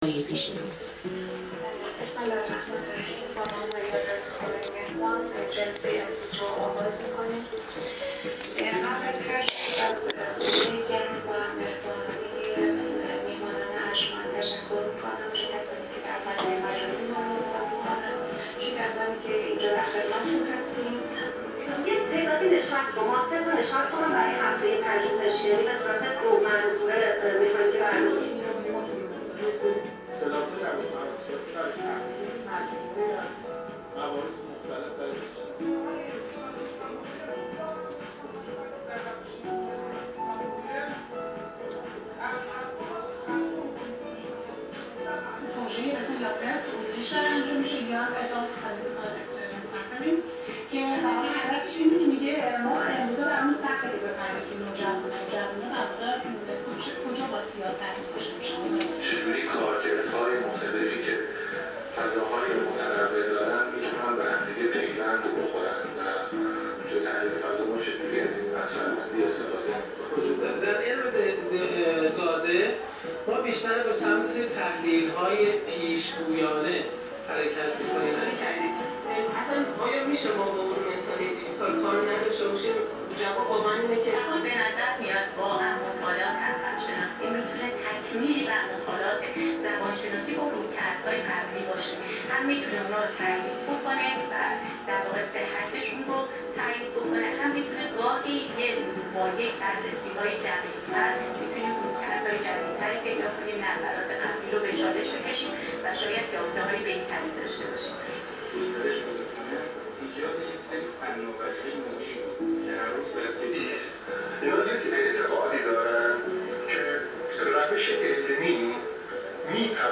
نخستین همایش ملی روش های پژوهش در علوم انسانی و اجتماعی ۲۹ بهمن ۱۴۰۲